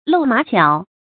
注音：ㄌㄡˋ ㄇㄚˇ ㄐㄧㄠˇ
露馬腳的讀法